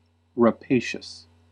Ääntäminen
Synonyymit lupine plunderous Ääntäminen US UK : IPA : /rəˈpeɪ.ʃəs/ US : IPA : /rəˈpeɪ.ʃəs/ Haettu sana löytyi näillä lähdekielillä: englanti Käännöksiä ei löytynyt valitulle kohdekielelle.